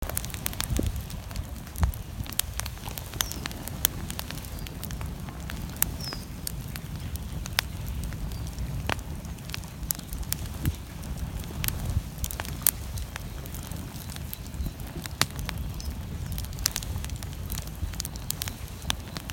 Fire-campfire-bonfire-Fire Sound Effects – Free Music Download For Creators
fire-campfire-bonfire-Fire_sound_effects.ogg